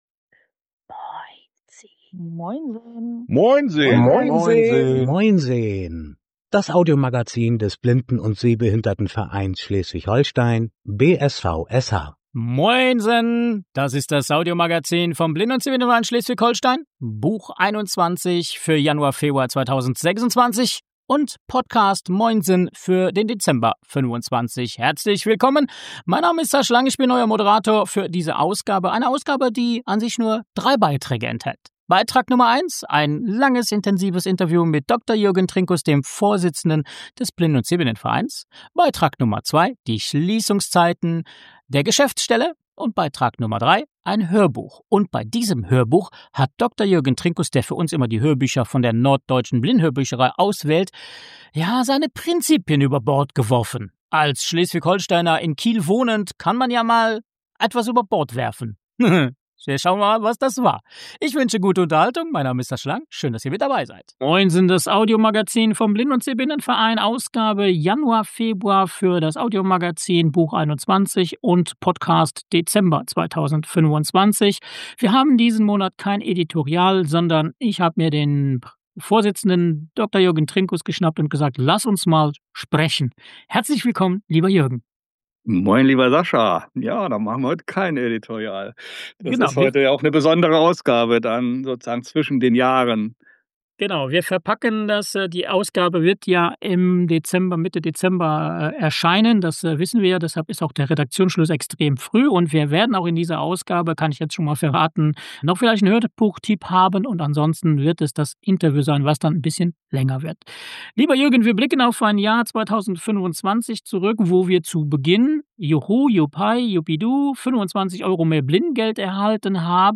Das Audiomagazin vom BSVSH